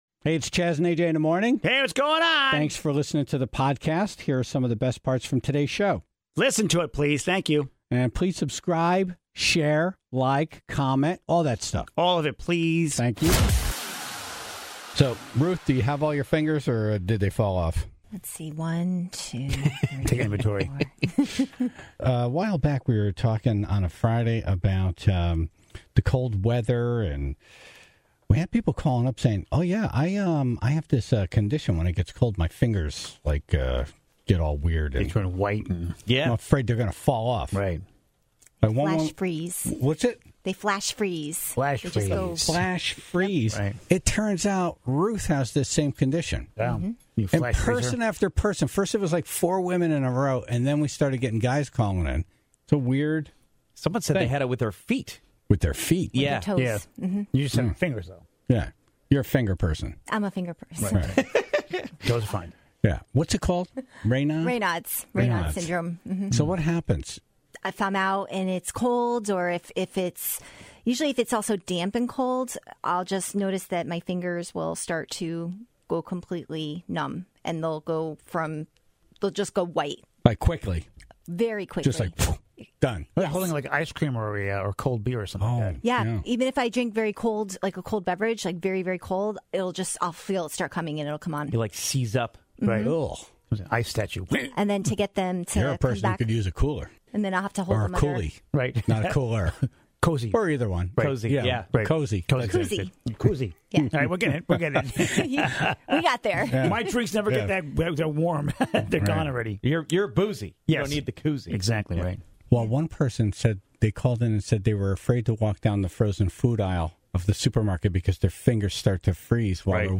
(4:50) Audio from a viral video of Richard Marx losing it on an audience member, and calls from the Tribe about the onstage rants they've witnessed during concerts. (20:04) Dumb Ass News - A growing trend in the Midwest, is convenience store bathrooms that turn into disco parties.